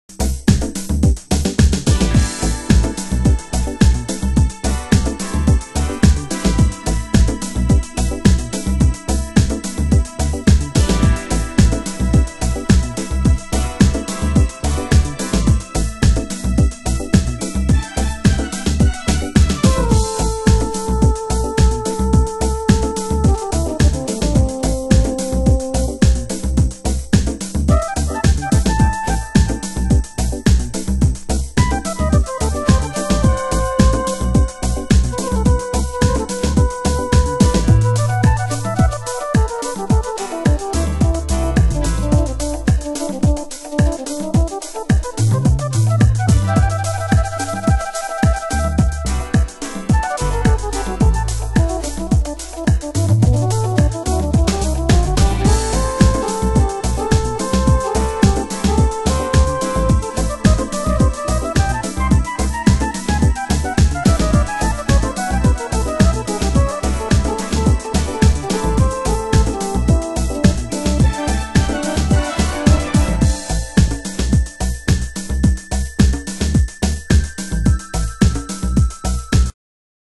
盤質：軽いスレ傷、少しチリパチノイズ有